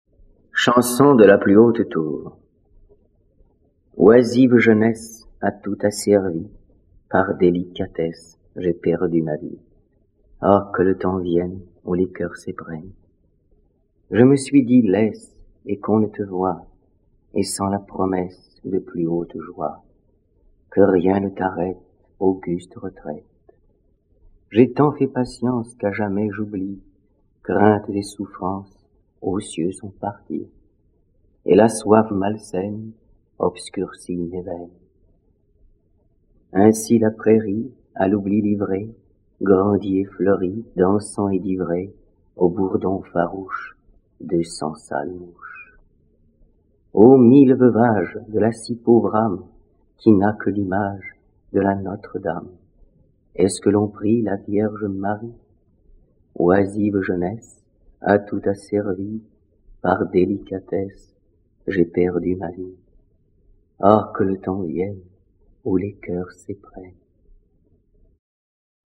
Dits par Michel Vitold, Prix du disque 1958